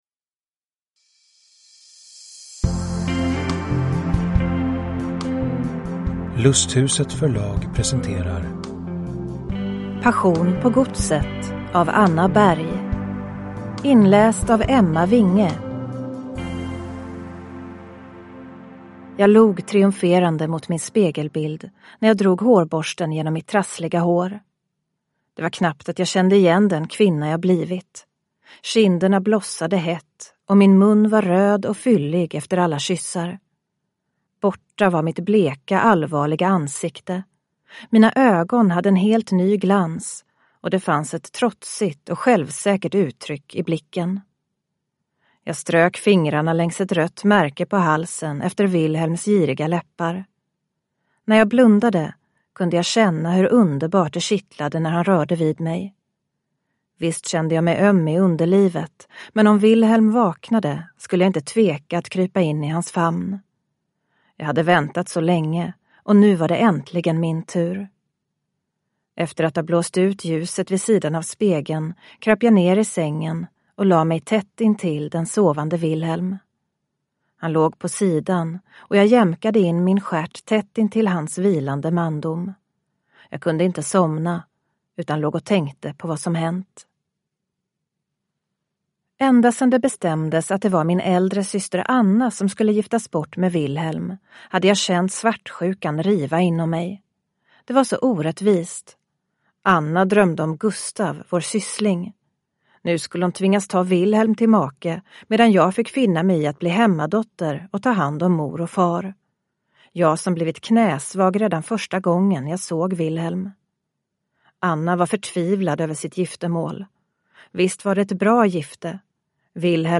Passion på godset (ljudbok) av Anna Berg